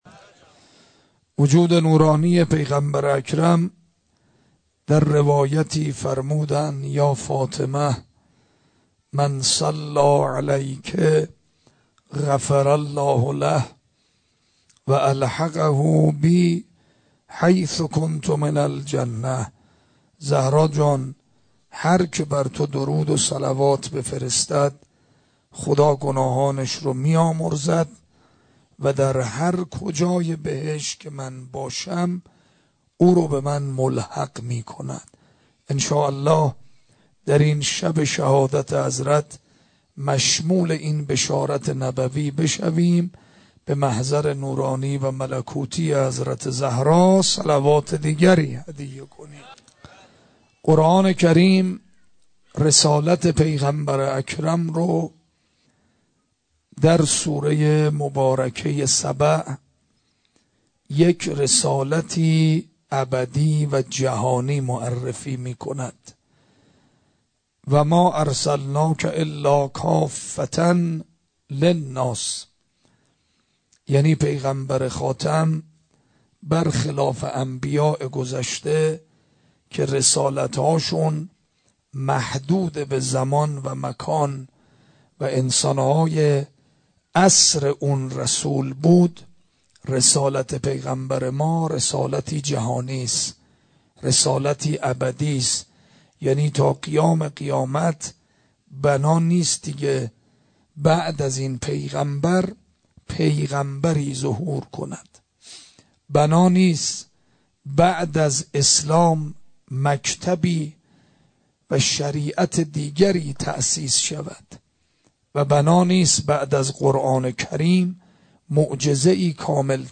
سخنرانی ۱۹_۱۱_۱۳۹۷_شیراز